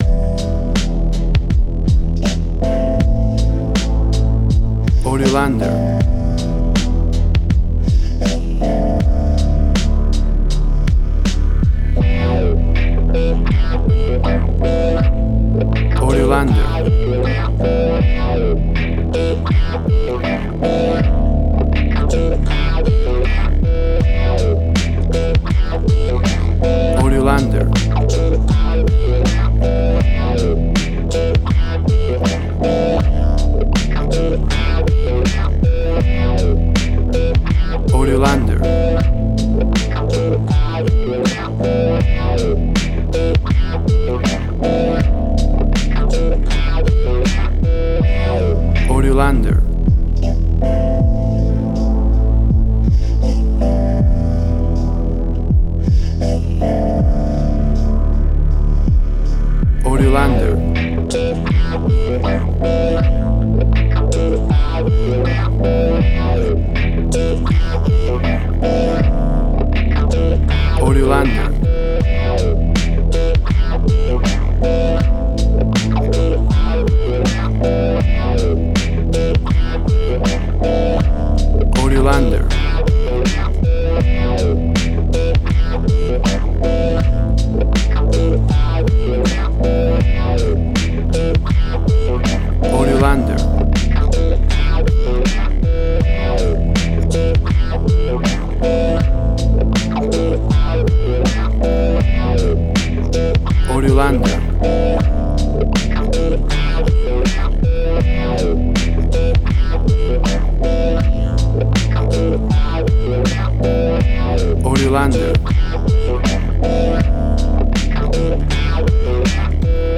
Long electronic field heartbeat and breathing FX
Tempo (BPM): 95